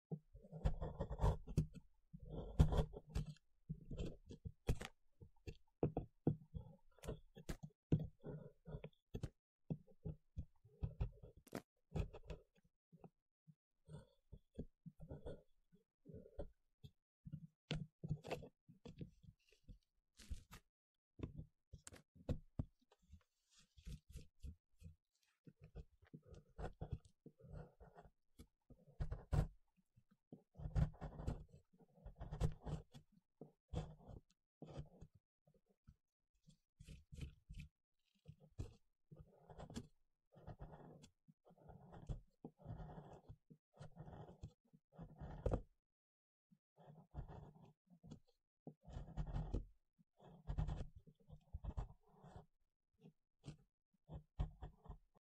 ASMR Excavation Dinosaur Fossil sound effects free download
ASMR Excavation Dinosaur Fossil - Rough and Intense Sounds (No Talking)